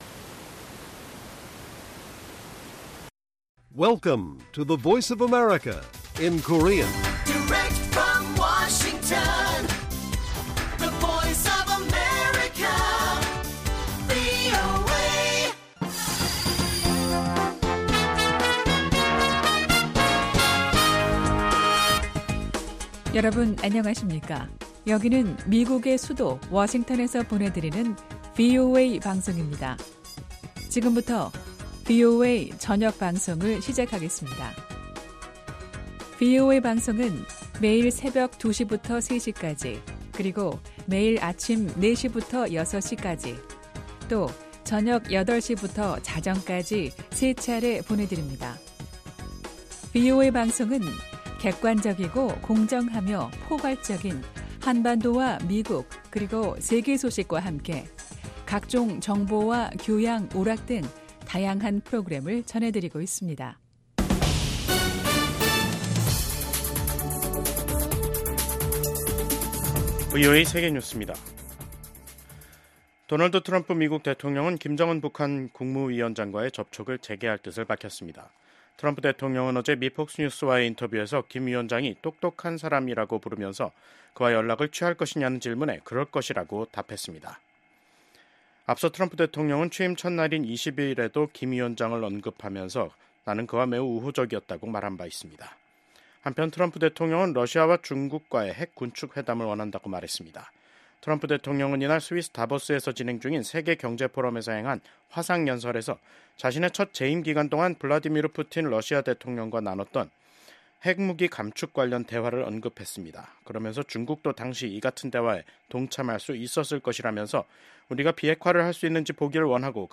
VOA 한국어 간판 뉴스 프로그램 '뉴스 투데이', 2025년 1월 24일 1부 방송입니다. 도널드 트럼프 미국 대통령이 김정은 북한 국무위원장과 다시 만날 것이라는 의지를 밝혔습니다.